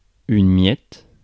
Ääntäminen
Ääntäminen France: IPA: [mjɛt] Haettu sana löytyi näillä lähdekielillä: ranska Käännös Ääninäyte Substantiivit 1. crumb US 2. breadcrumb US Suku: f .